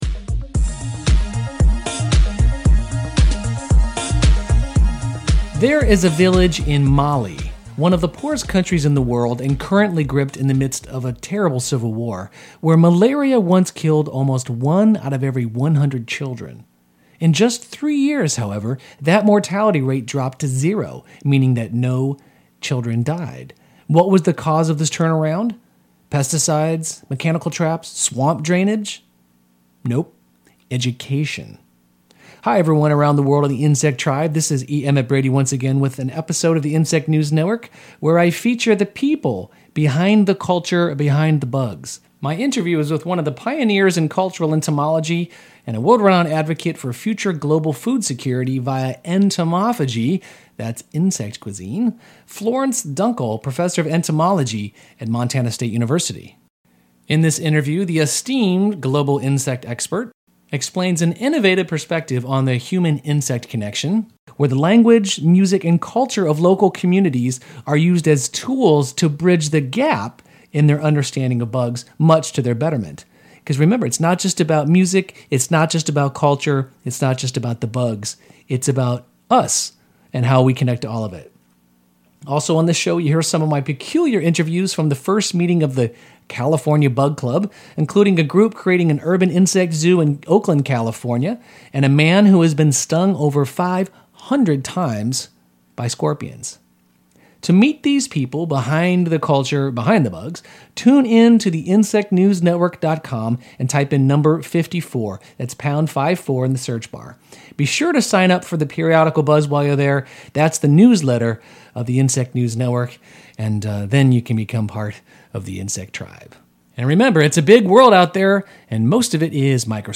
My interview is with one of the pioneers in Cultural Entomology and a world-renown advocate for future global food security via Entomophagy (that insect cuisine) -